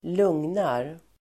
Uttal: [²l'ung:nar]